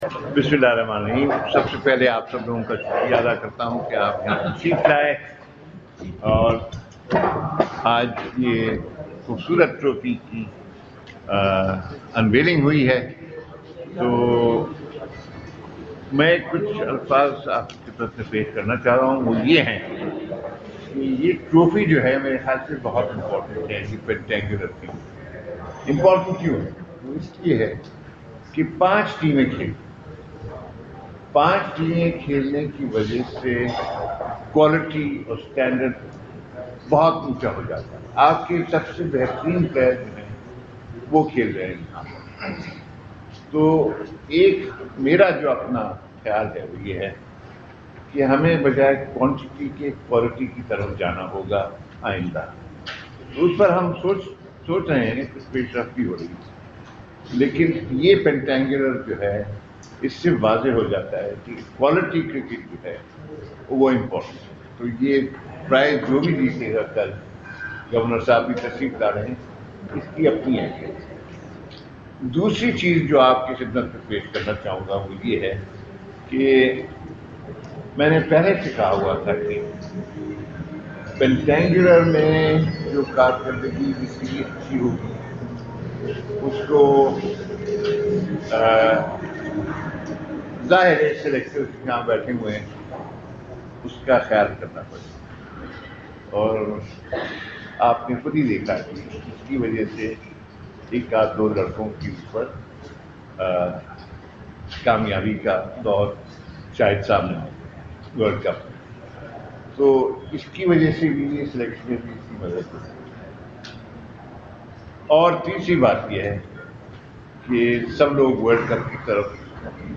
Chairman PCB Mr. Shaharyar M. Khan media talk at National Stadium, Karachi (Audio)